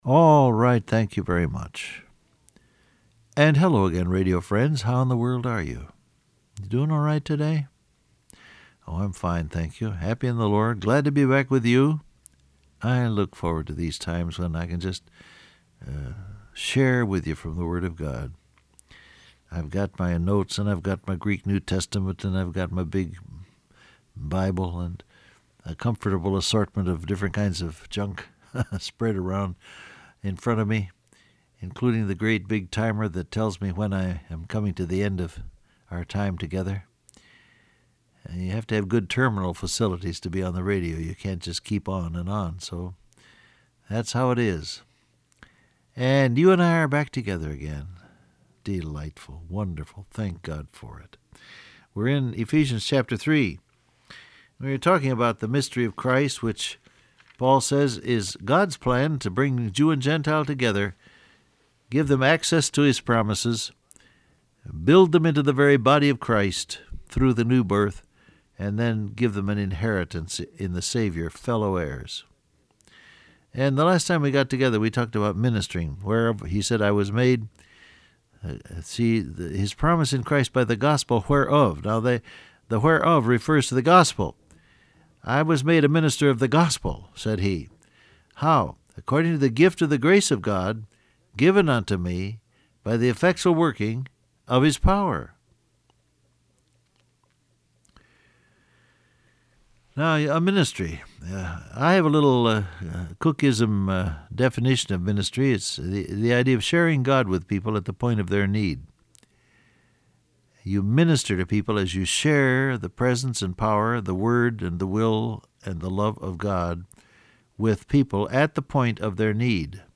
Download Audio Print Broadcast #7306 Scripture: Ephesians 3:7 , Philippians 2:13 Topics: Surrender , Prayer , Trials , The Holy Spirit Transcript Facebook Twitter WhatsApp Alright, thank you very much.